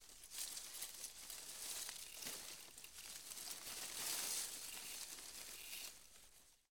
Leaves Rustle, Heavy; Leaves Rustling And Movement. - Rustling Leaves